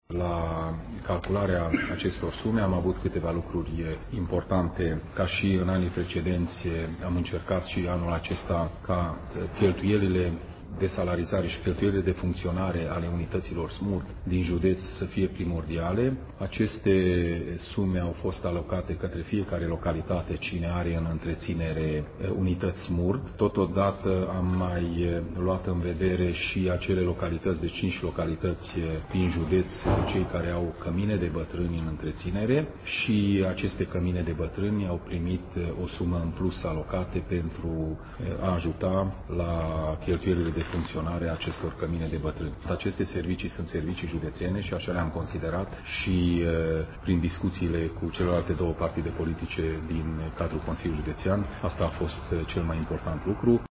Președintele Consiliului Județean Mureș, Peter Ferenc.